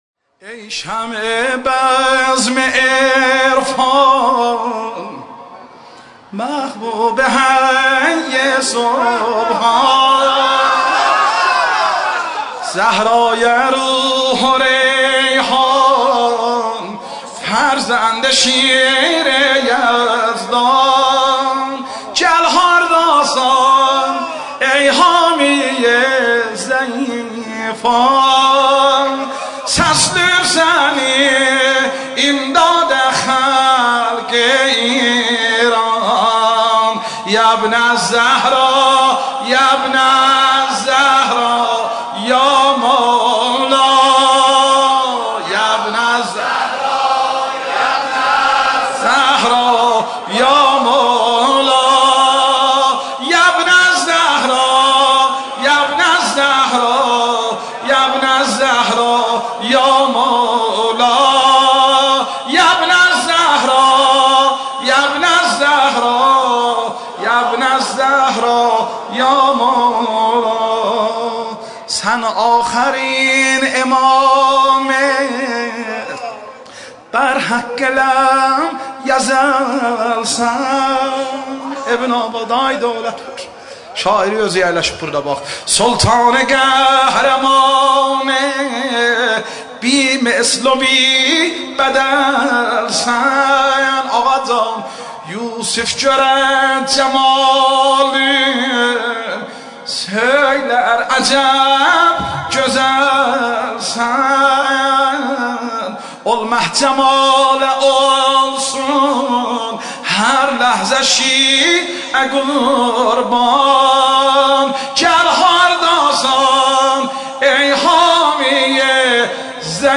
مولودی نیمه شعبان ترکی